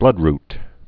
(blŭdrt, -rt)